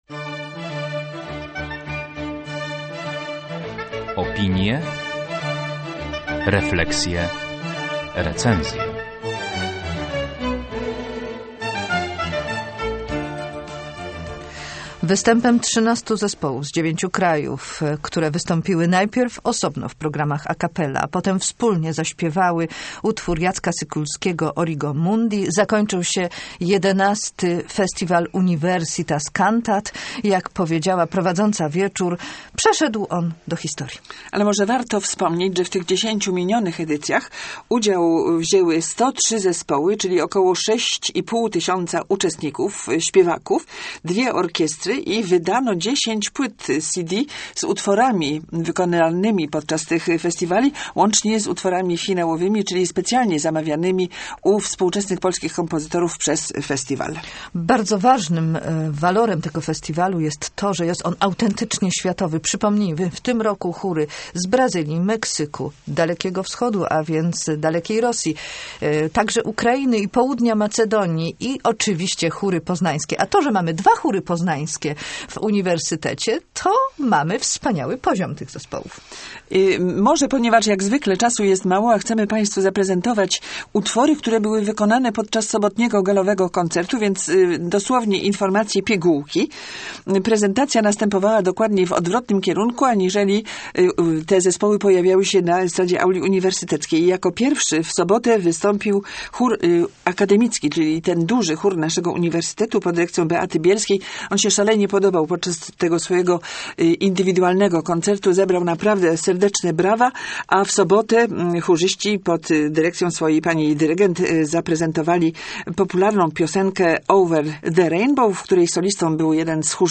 Finał Festiwalu Chórów Uniwersyteckich
Na koncercie finałowym XI Festiwalu Universitas Cantat 450 śpiewaków z 13 chórów z Europy, Azji i Ameryki Środkowej dokona prawykonania kompozycji Jacka Sykulskiego "Origo Mundi".